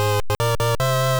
game_won.wav